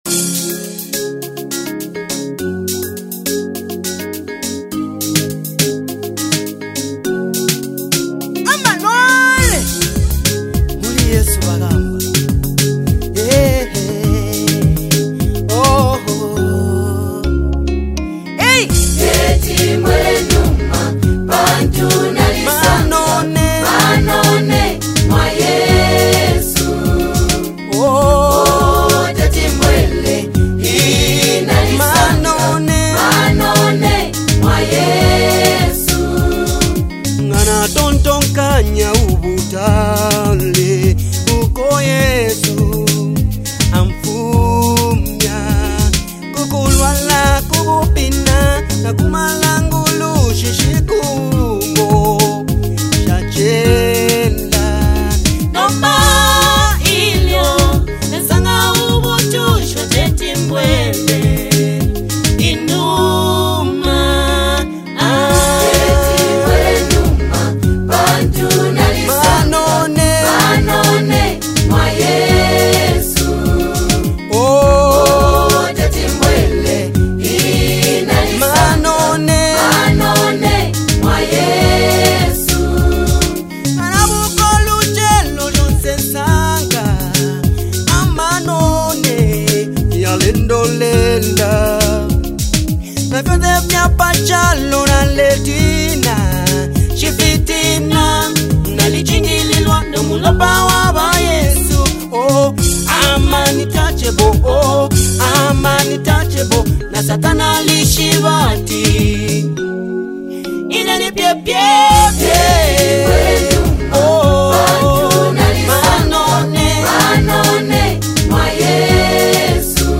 gospel
" a joyous track from one of Zambia’s finest gospel talents.